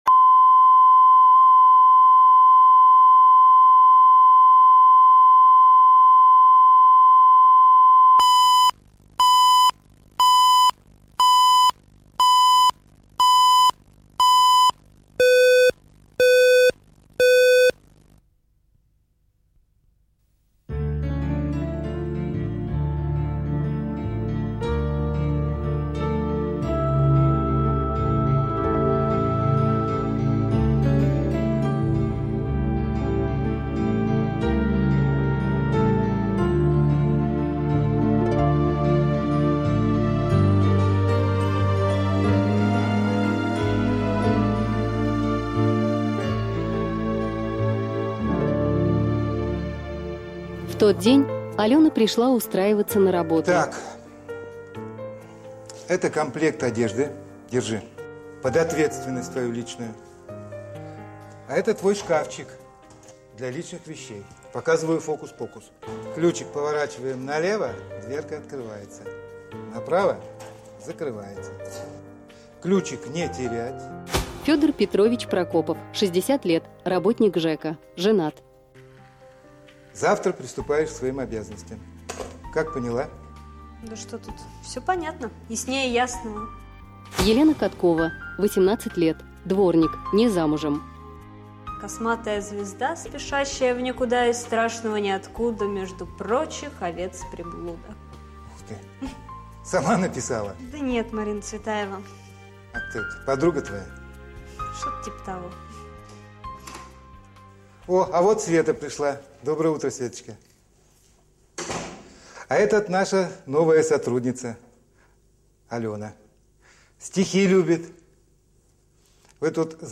Аудиокнига Бабовщина